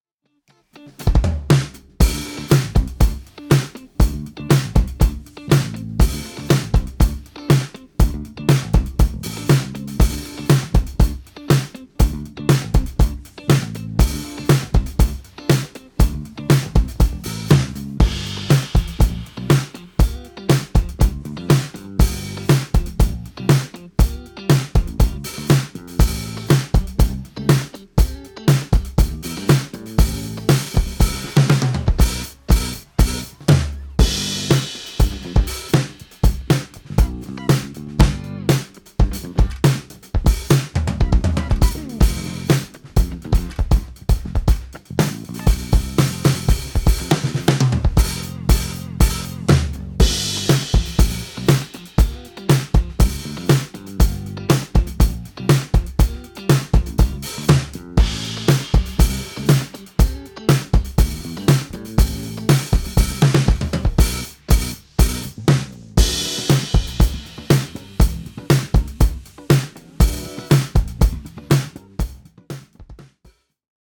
Record pro sounding drums for your song
• Top Sounding drum recording
Drummer Recording Drums Acoustic Professional
Funk Loops_EAR2tUFemY.mp3